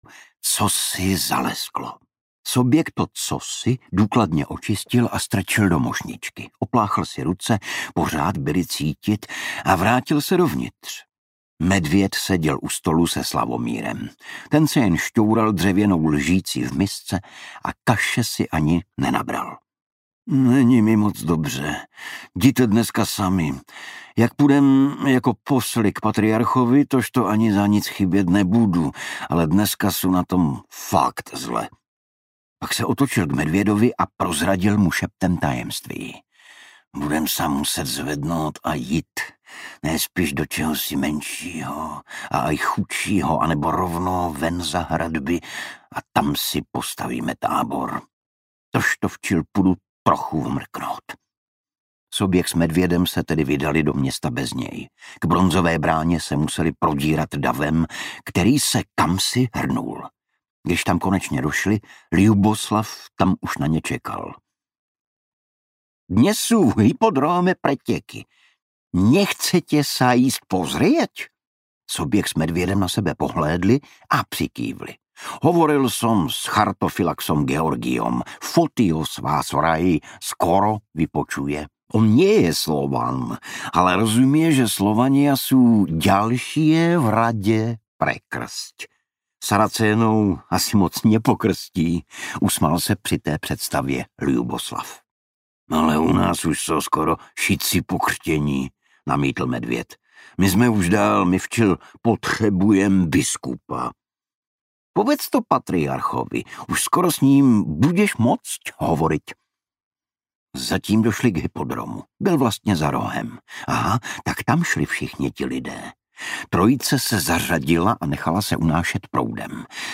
Vítej u nás, Metude audiokniha
Ukázka z knihy
• InterpretIgor Bareš